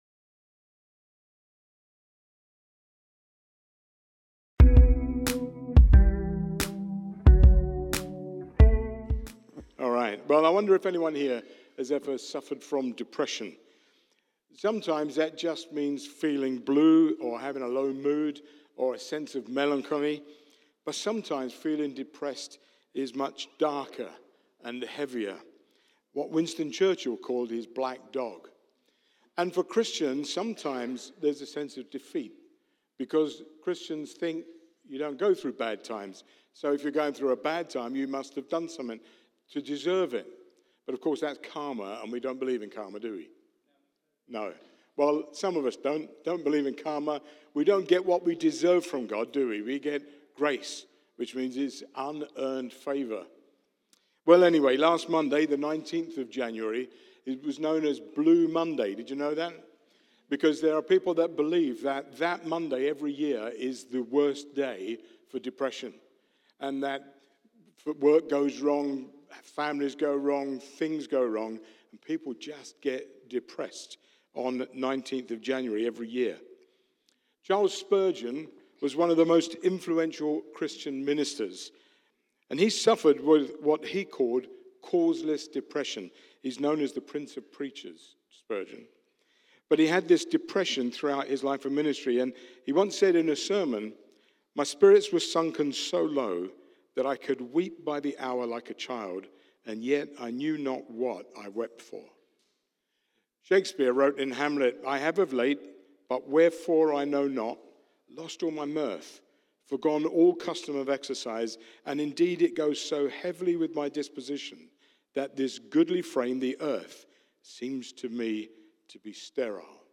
Sunday Messages | Meeting God in a Dark Place